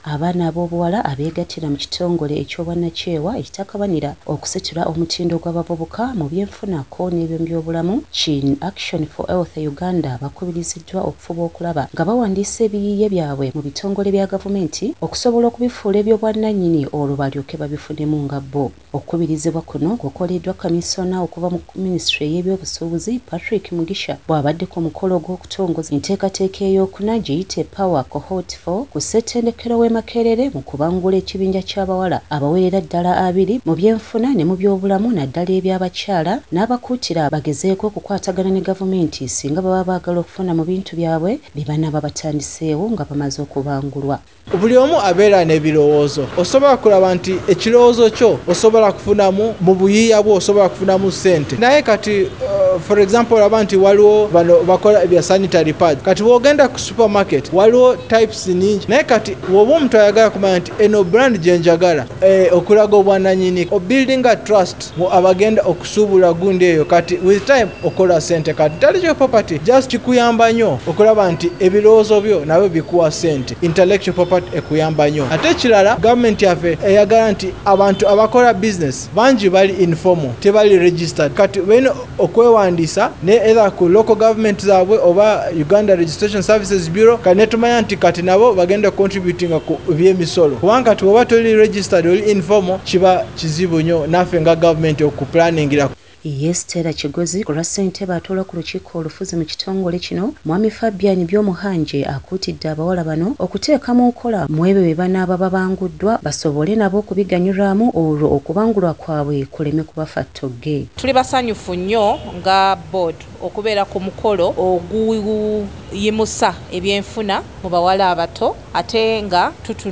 Radio story in Luganda: